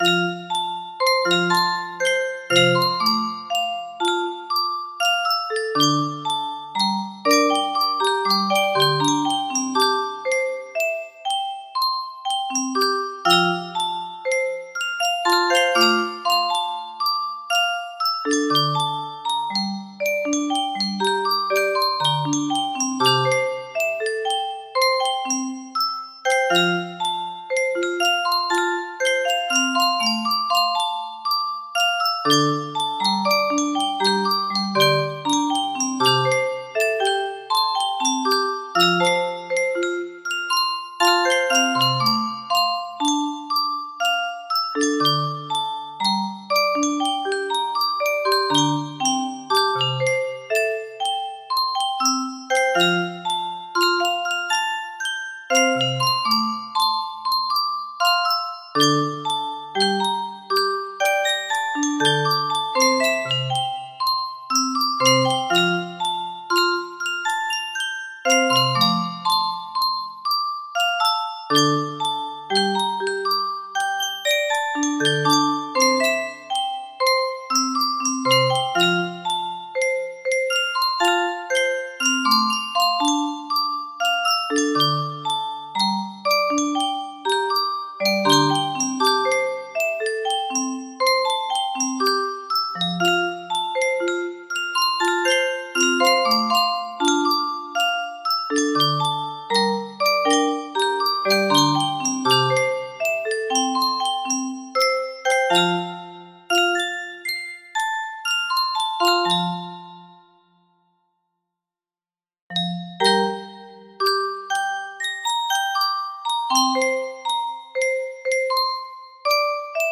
Full range 60
Traditional Japanese stringed musical instrument